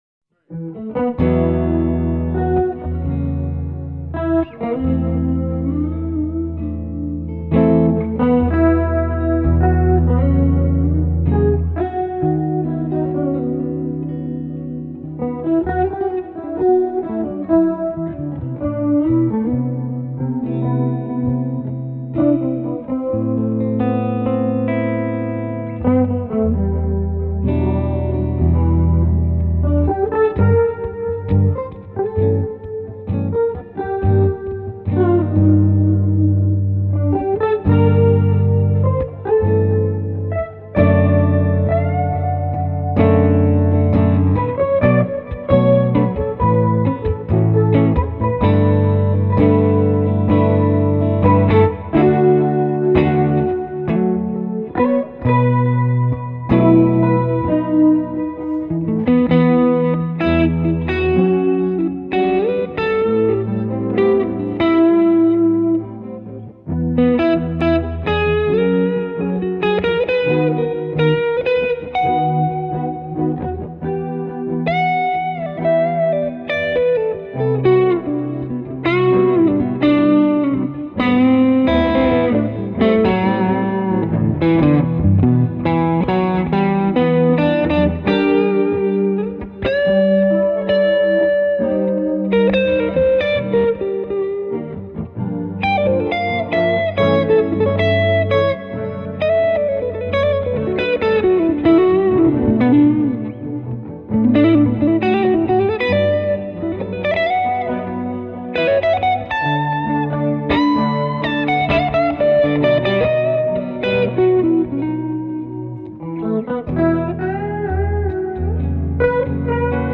guitar duos
an anthemic jazz -rock piece